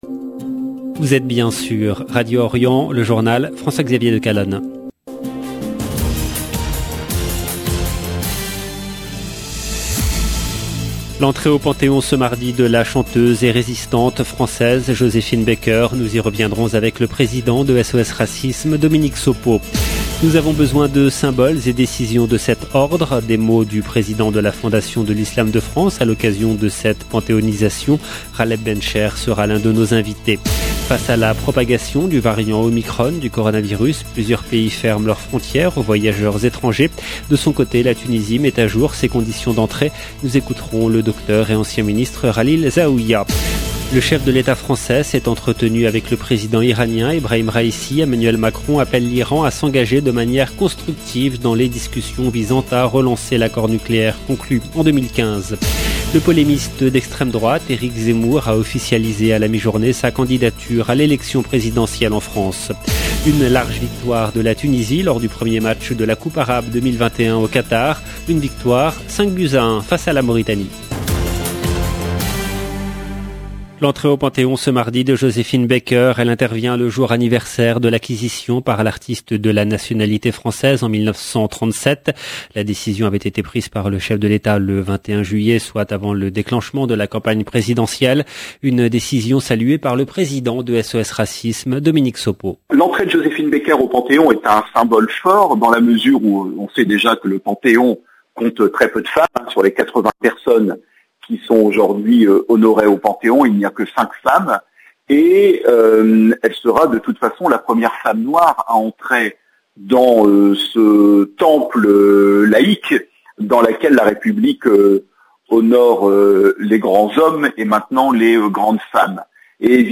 LE JOURNAL EN LANGUE FRANCAISE DU SOIR DU 30/11/21